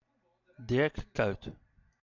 Dirk Kuijt (Dutch: [ˈdɪr(ə) ˈkœyt]
Nl-Dirk_Kuyt.oga.mp3